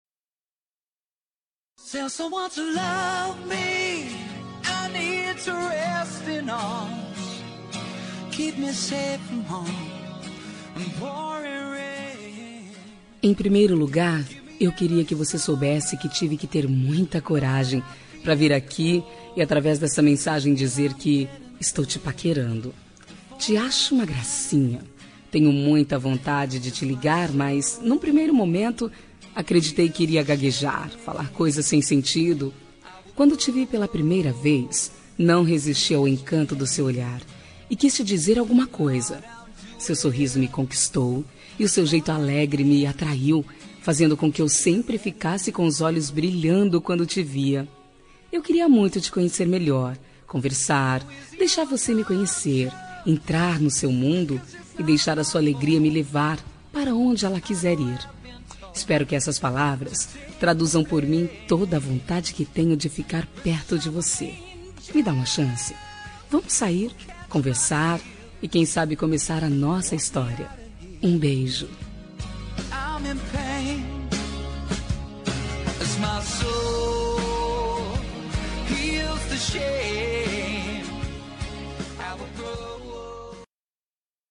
Telemensagem de Paquera – Voz Feminina – Cód: 021481
Paquera vamos conversar -Fem 2148.mp3